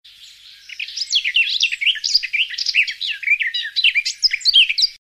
Переливы птиц